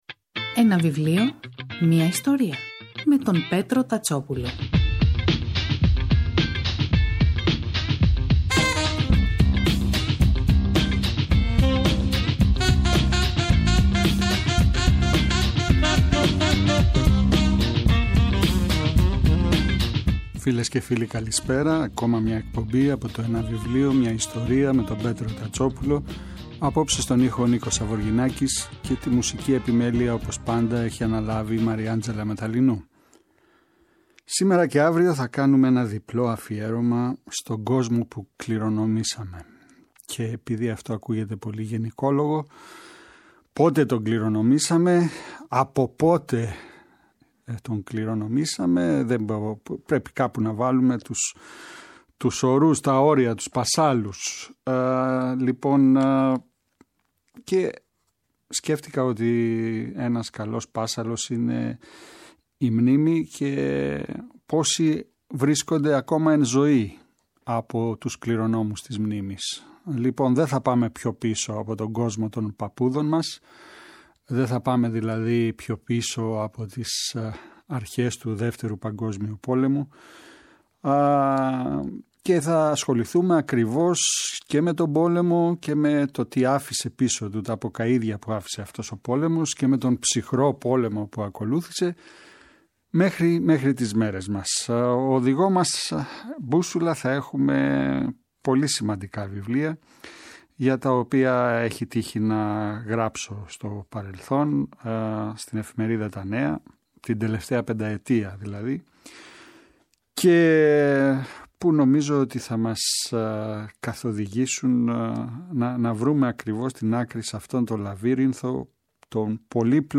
Αυτό το Σαββατοκύριακο, 11 και 12 Νοεμβρίου, στις 5 το απόγευμα, στη ραδιοφωνική εκπομπή «Ένα βιβλίο, μια ιστορία» στο Πρώτο Πρόγραμμα Διπλό αφιέρωμα στον «Κόσμο που κληρονομήσαμε» παρουσιάζονται και σχολιάζονται τα βιβλία :